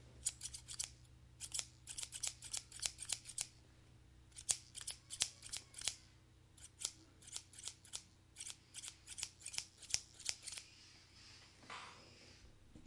描述：使用变焦h6快速剪断一些剪刀
Tag: 理发 剪断 剪刀 切片